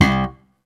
JS POP #2 C2.wav